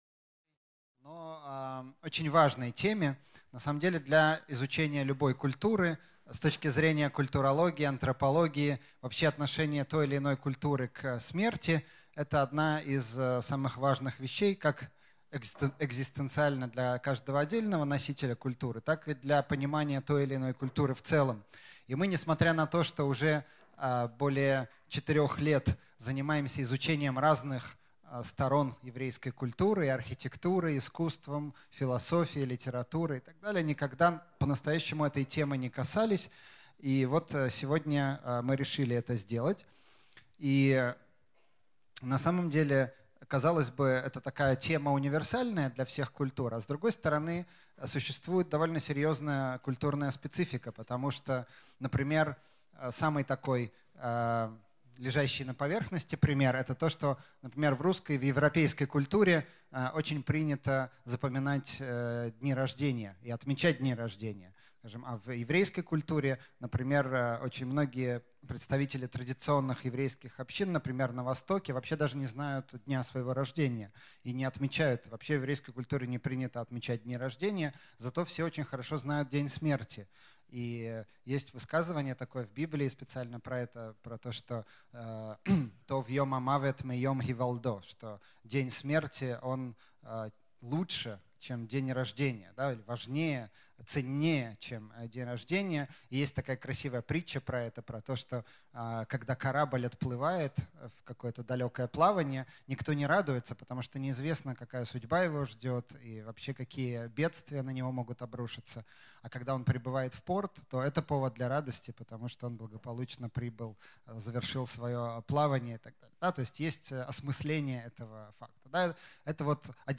Аудиокнига Символика еврейского похоронного обряда | Библиотека аудиокниг